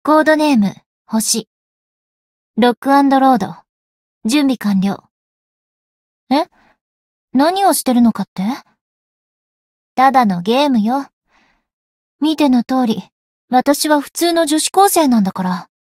灵魂潮汐-星见亚砂-人偶初识语音.ogg